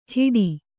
♪ hiḍi